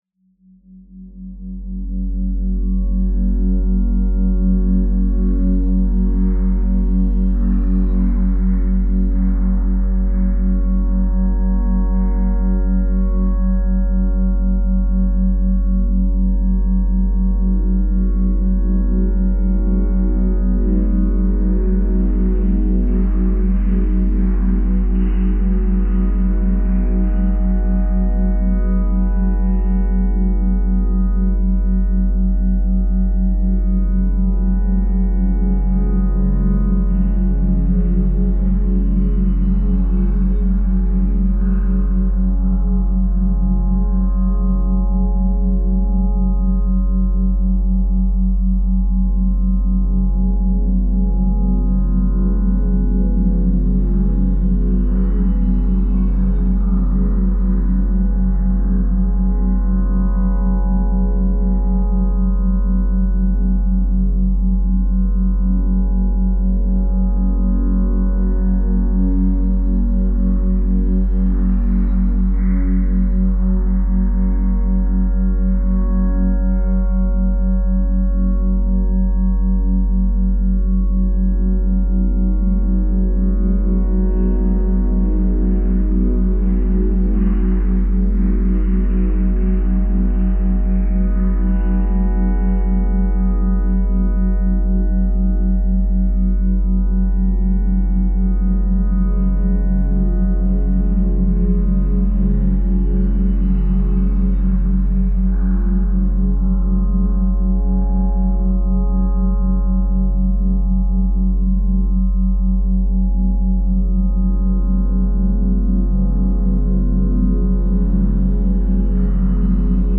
82hz - Gamma Binaural Beats for Mental Clarity